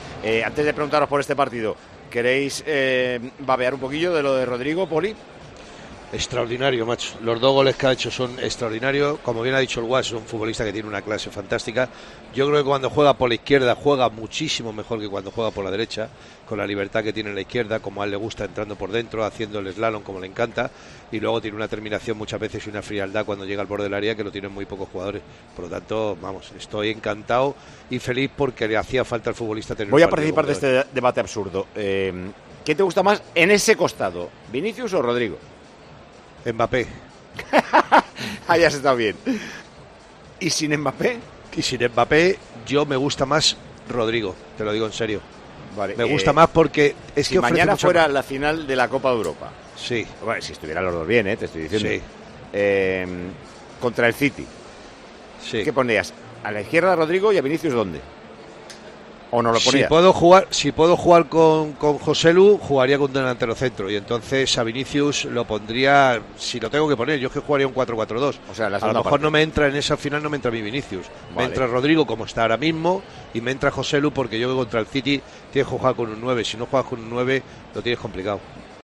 El comentarista de Tiempo de Juego elige a uno de los dos jugadores del Real Madrid para una hipotética final de champions.